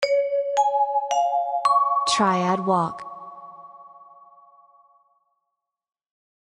Chimes for announcements, etc.